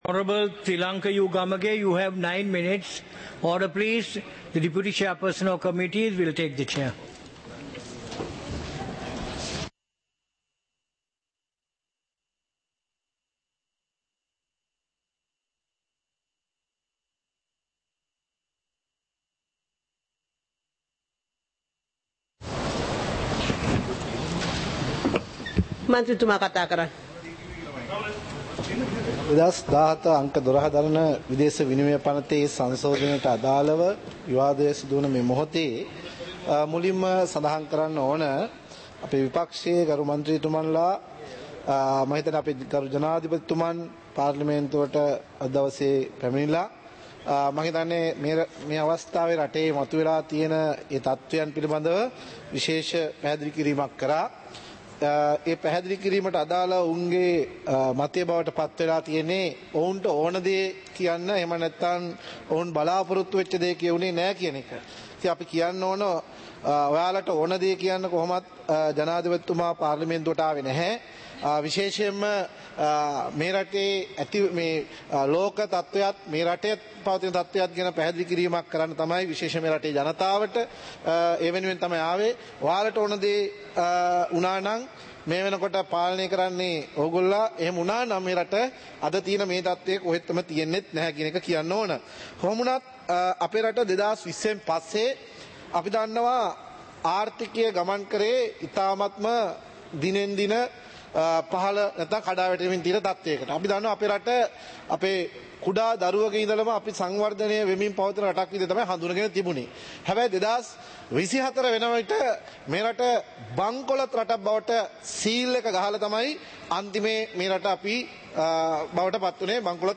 இலங்கை பாராளுமன்றம் - சபை நடவடிக்கைமுறை (2026-03-03)